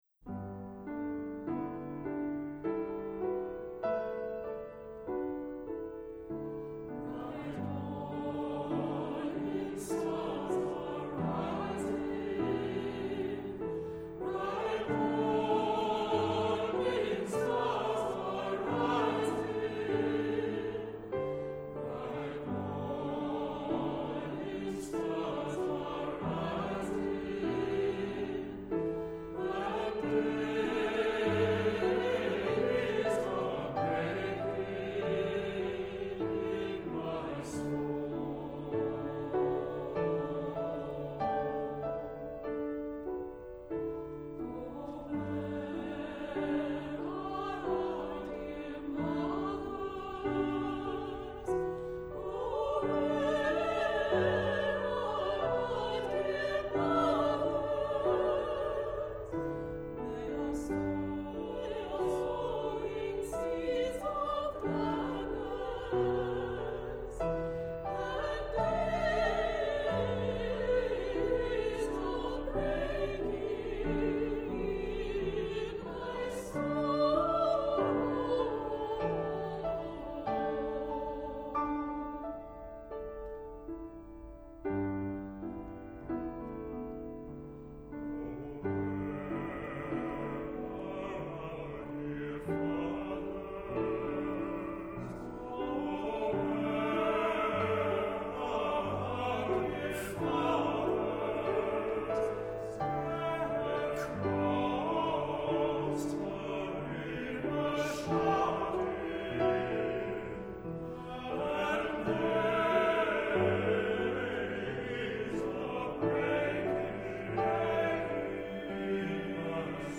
TTBB a cappella